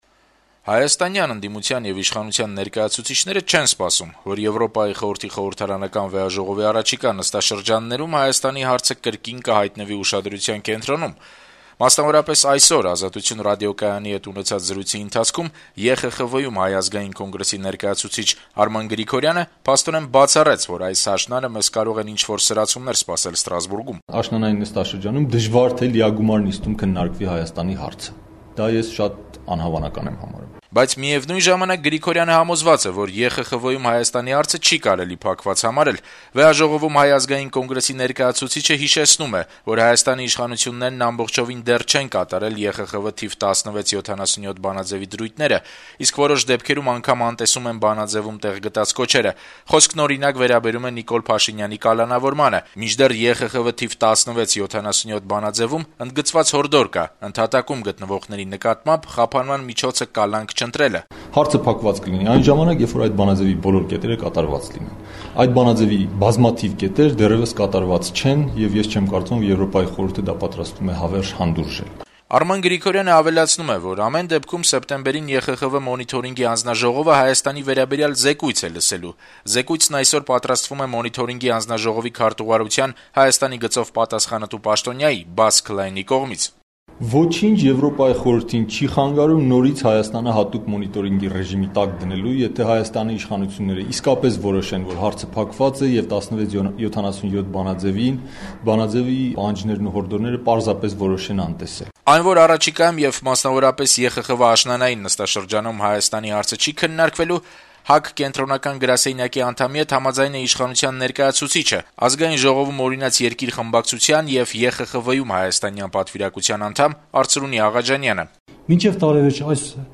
«Ազատություն» ռադիոկայանի հետ զրույցում